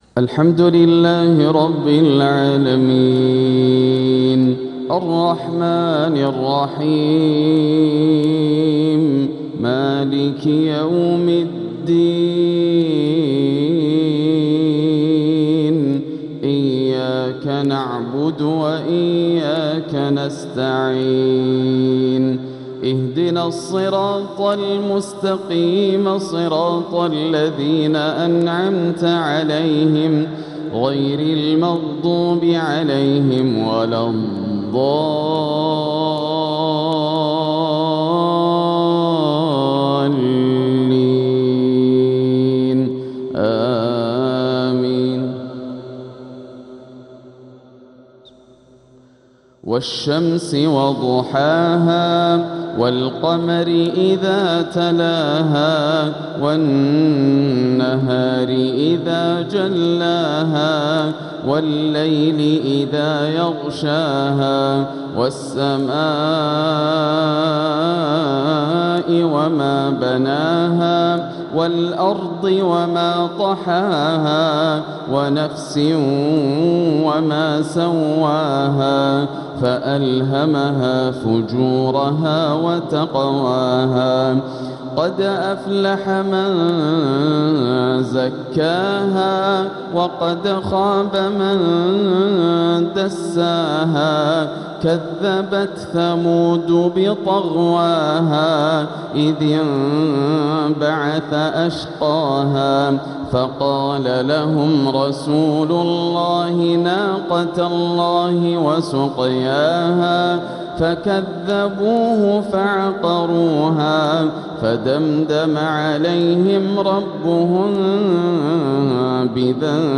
تلاوة لسورتي الشمس والتين | مغرب الأحد 2-2-1447هـ > عام 1447 > الفروض - تلاوات ياسر الدوسري